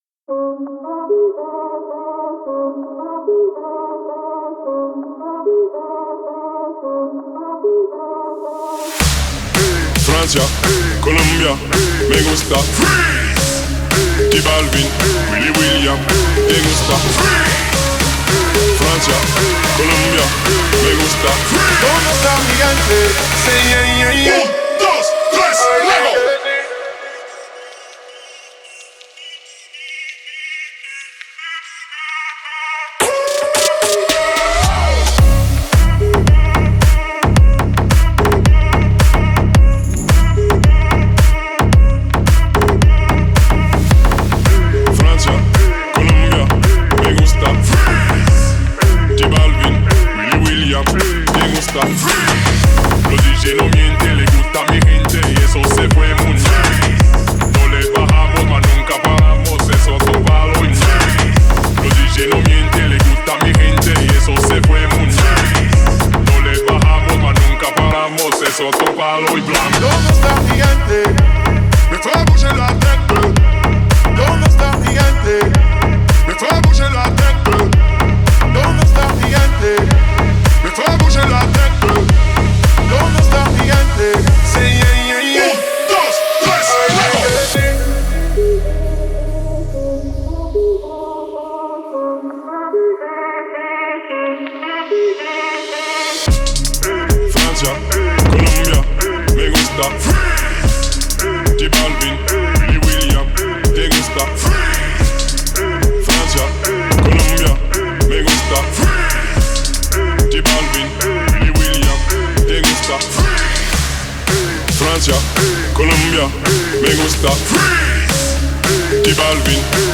это зажигательный трек в жанре реггетон и электронной музыки